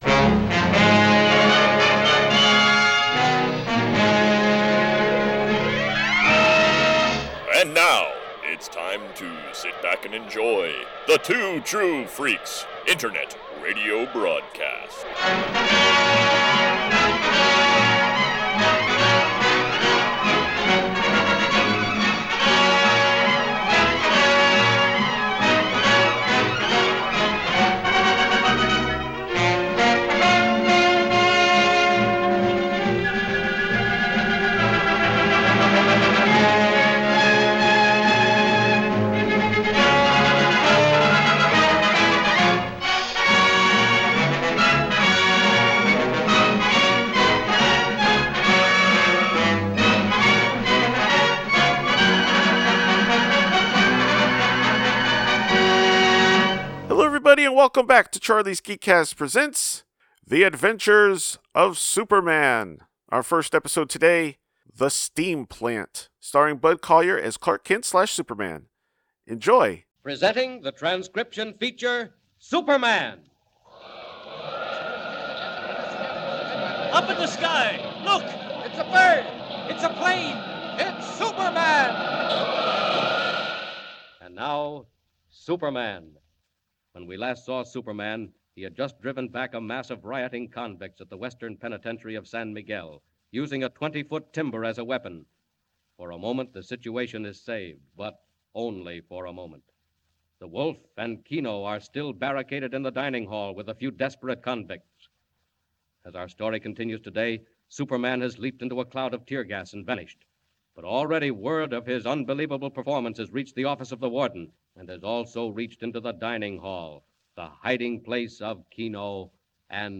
See author's posts Tagged as: radio series , Bud Collyer , Metropolis , clark kent , Daily Plant , Superman , Lois Lane , Perry White , Krypton . email Rate it 1 2 3 4 5